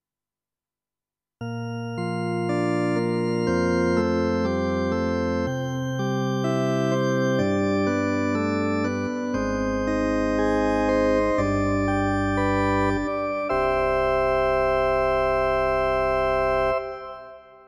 12. I SUONI - GLI STRUMENTI XG - GRUPPO "ORGAN"
XG-02-07-EvenbarOrg.mp3